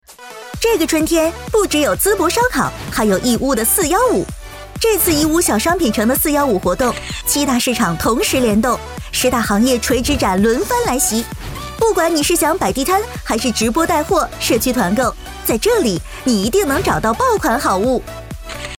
广告-女26-欢快-义乌.mp3